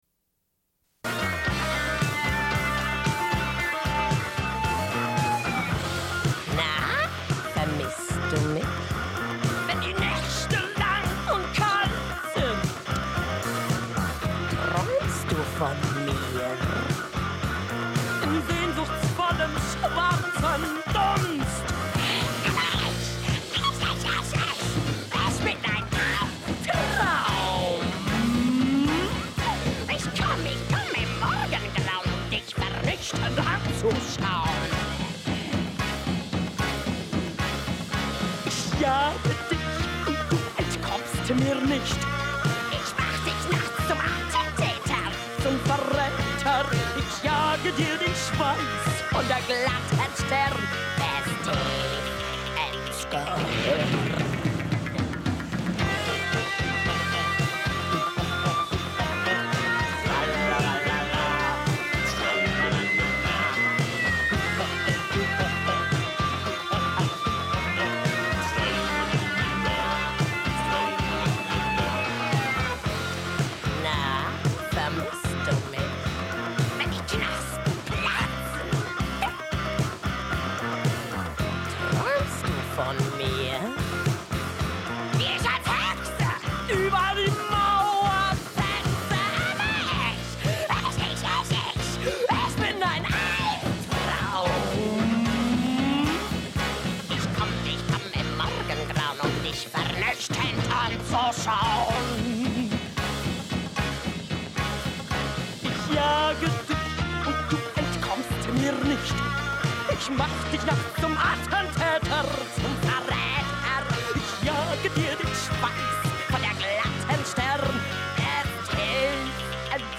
Une cassette audio, face A
Genre access points Radio